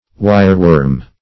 Wireworm \Wire"worm`\, n. (Zool.)